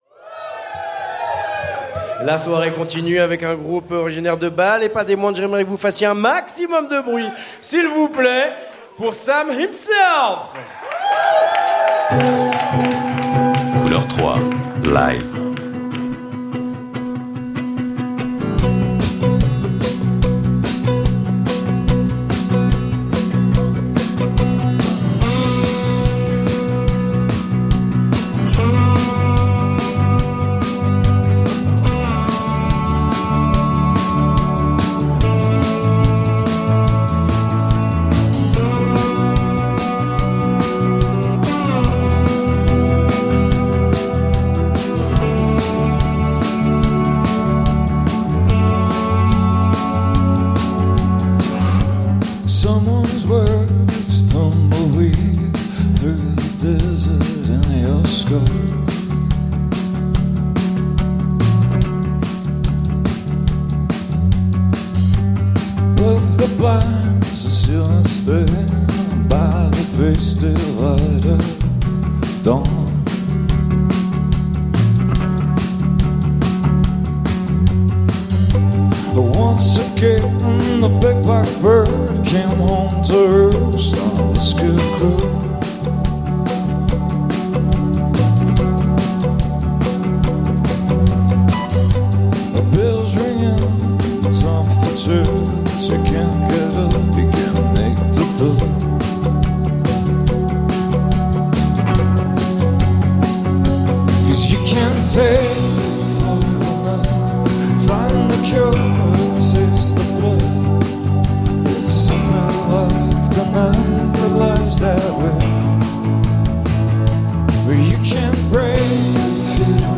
Live At Fri-Son - We Love CH Music Festival 2022
smokey baritone vocals